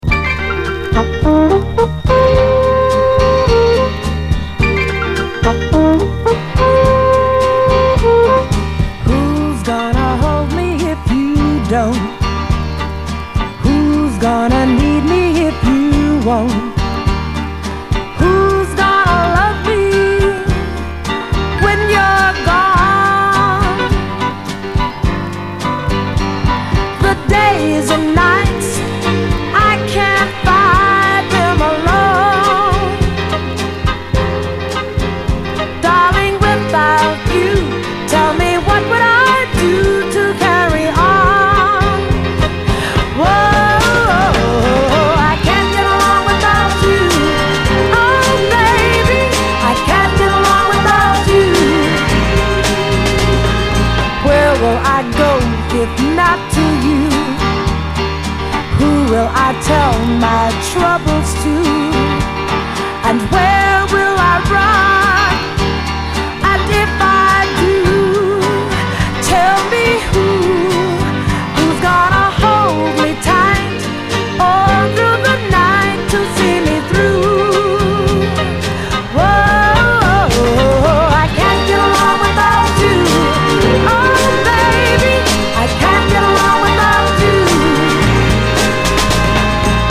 SOUL, 60's SOUL, 70's～ SOUL, 7INCH